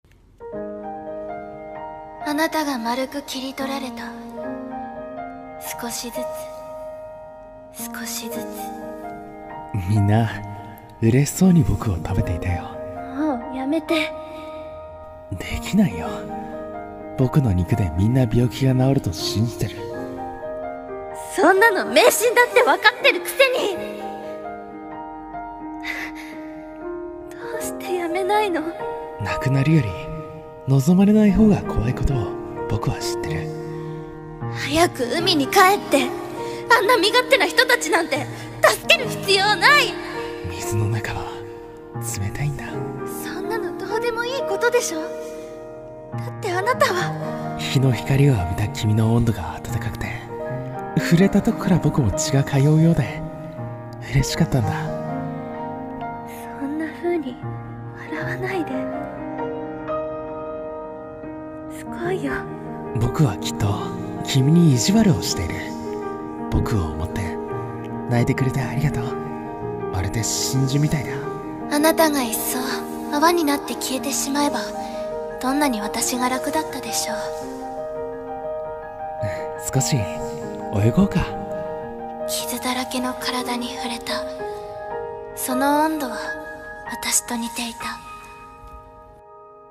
二人声劇【真珠と体温】